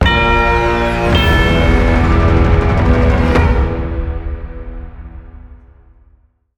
Music stinger for the player's death